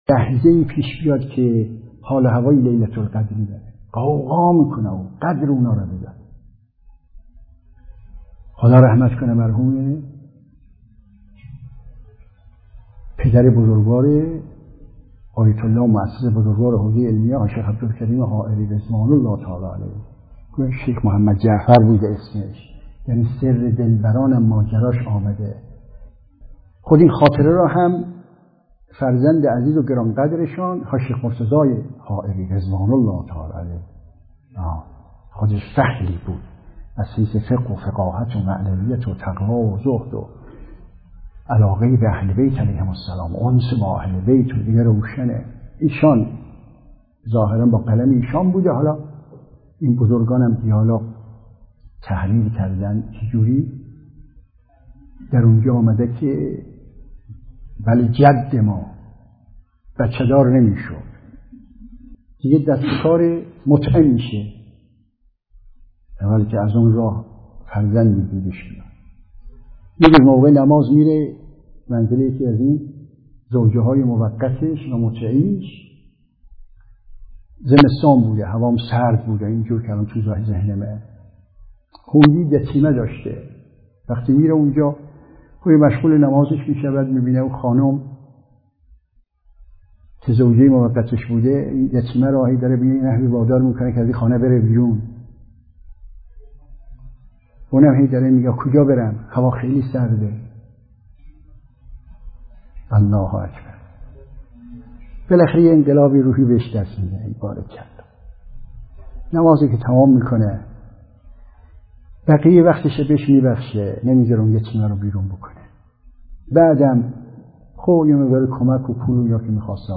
برکات گذشتن از یک شهوت حلال قسمتی از درس اخلاق مدرسه فقهی امام محمد باقر علیه‌السلام چهارشنبه (13آذر98)